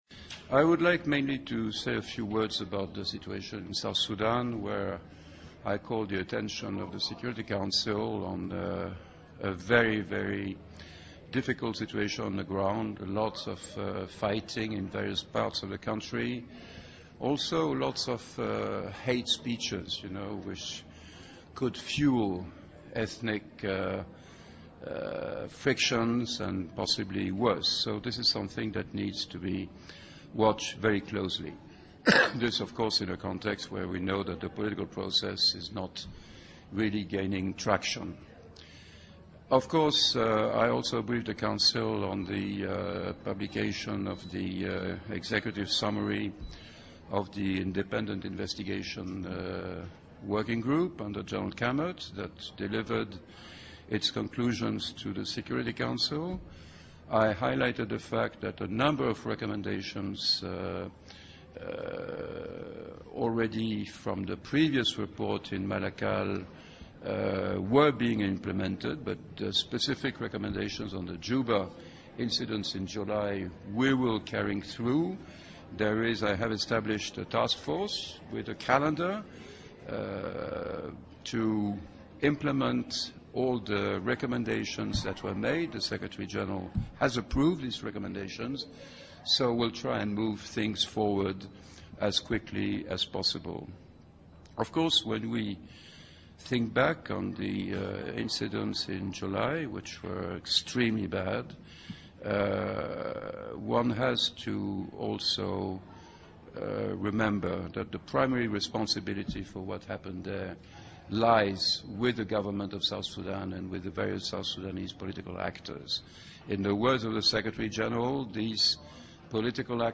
He later spoke to reporters in New York who asked what impact the move by the Government of Kenya to withdraw its peacekeepers will have on the UN mission in South Sudan.